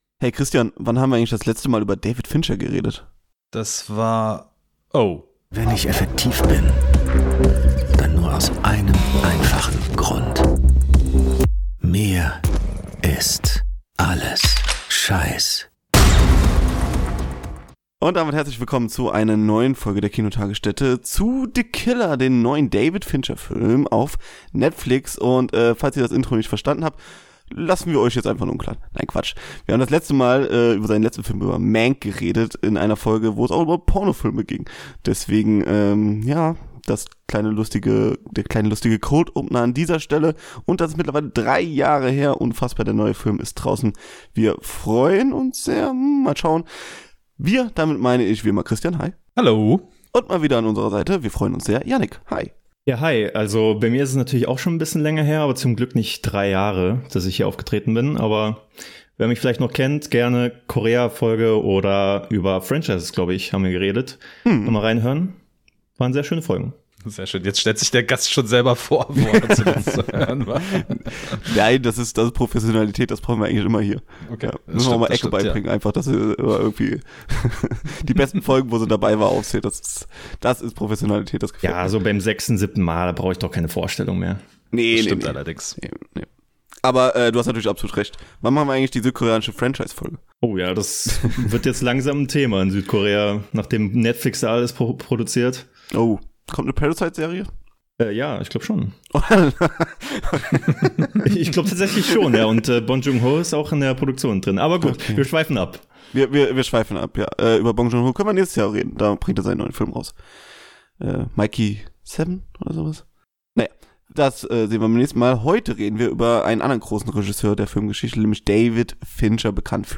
The Killer | Review-Talk ~ Die Kinotagesstätte Podcast